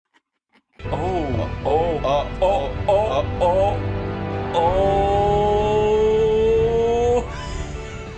Oh oh oh oh sound effect download
beautiful-little-trap3_part.mp3